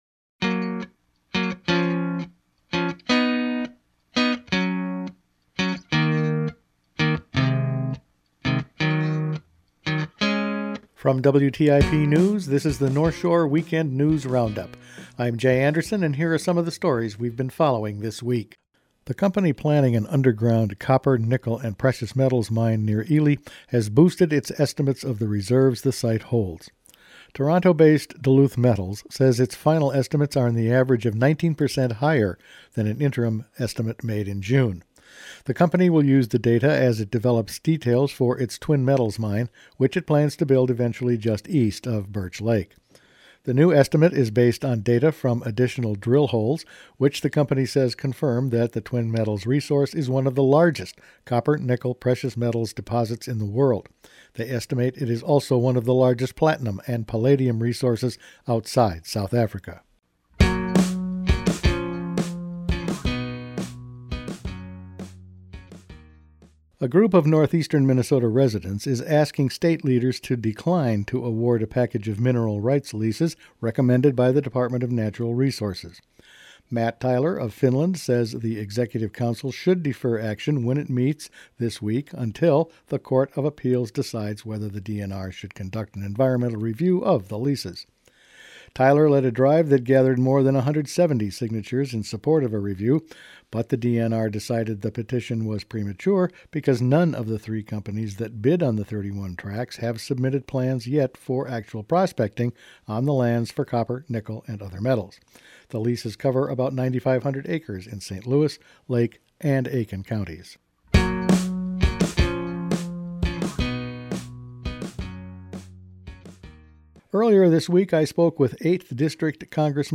Each weekend WTIP news produces a round up of the news stories they’ve been following this week. Twin Metals precious metals estimates go up, Arrowhead residents want prospecting delayed, new Congressman Rick Nolan weighed in on his return to the hill and clean air advocates want to get the haze out of the BWCA…all in this week’s news.